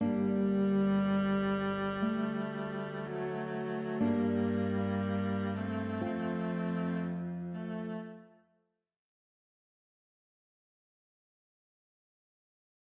La tonalité globale du morceau est sol mineur.
Pour des raisons de clarté auditive, les exemples audios seront ici donnés avec des sons de violoncelle, ceux ci étant préférables aux sons de voix synthétiques.
Tout commence sur un unisson des deux voix, qui se scindent ensuite sur la seconde moitié de la première mesure par le retard très expressif de note sensible.